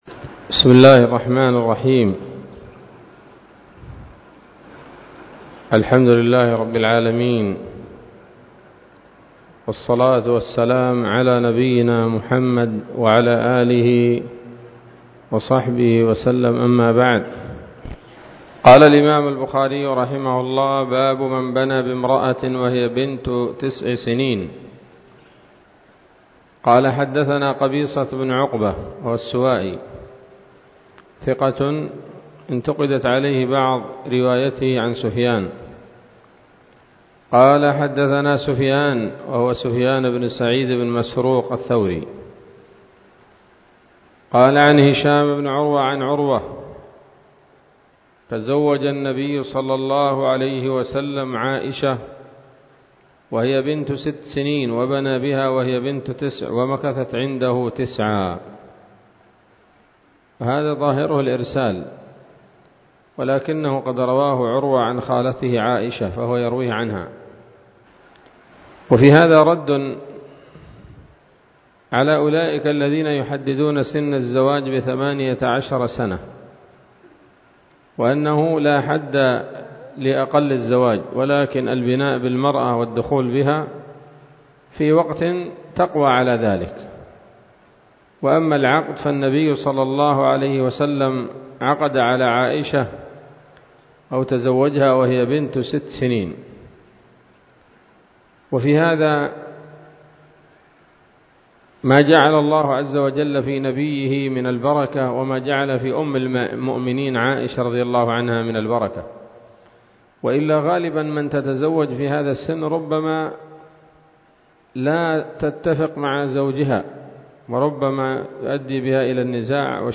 الدرس الثاني والخمسون من كتاب النكاح من صحيح الإمام البخاري